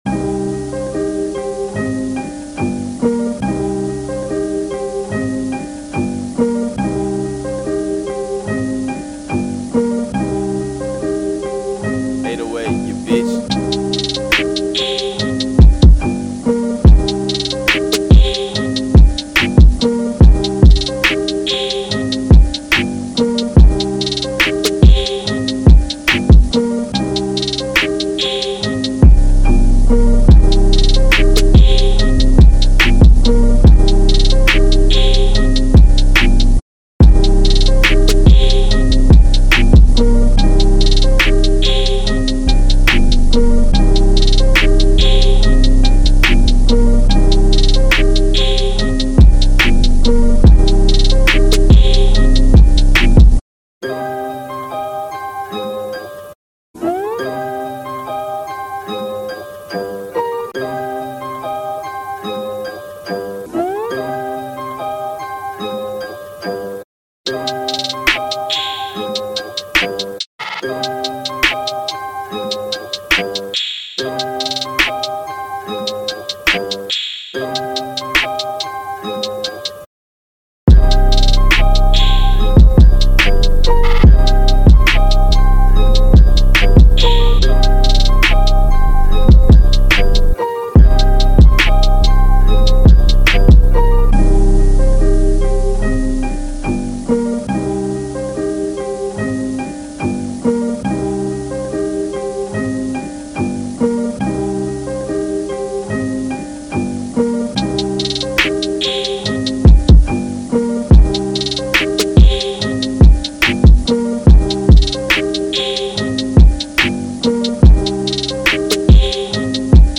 Description : Hip Hop sound